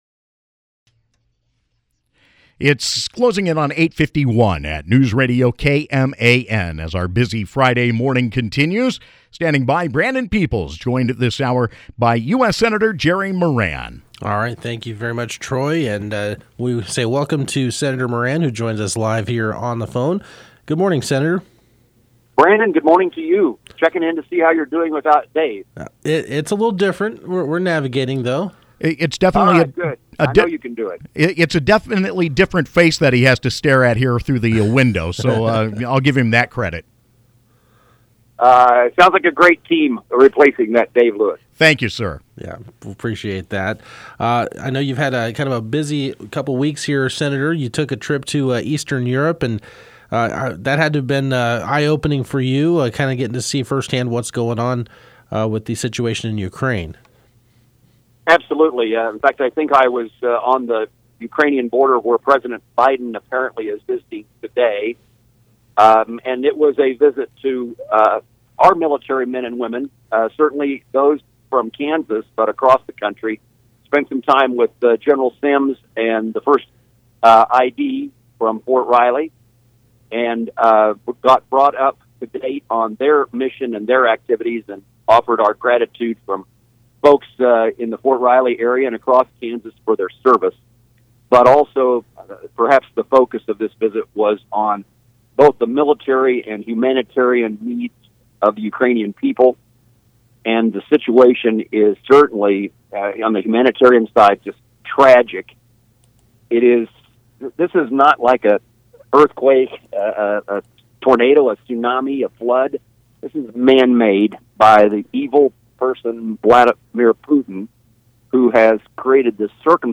U.S. Sen. Jerry Moran (R-Kan.) called in Friday to the KMAN Morning Show Friday at 8:50 a.m. and provided an update on the situation in Ukraine following his recent trip to Eastern Europe and provided thoughts on the nominating process for Supreme Court Justice nominee Ketanji Brown Jackson.